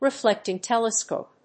アクセント・音節re・fléct・ing tèlescope